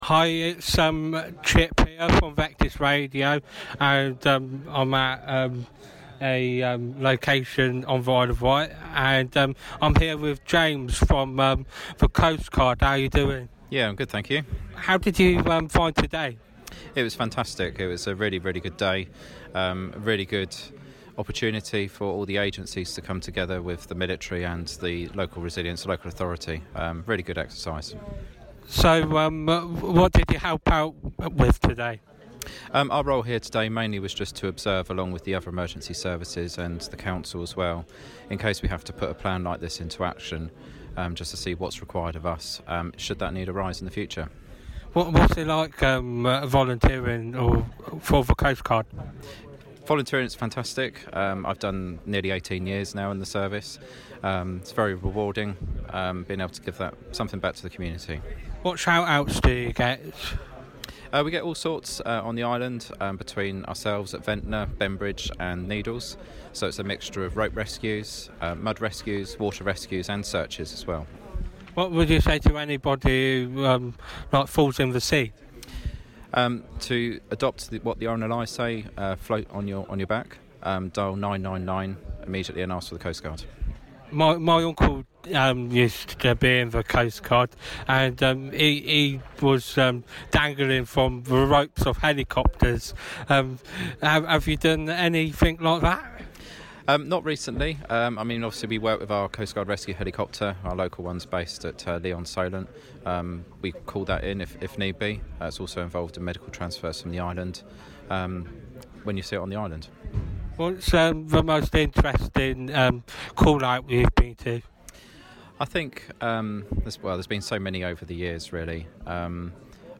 Interview 2022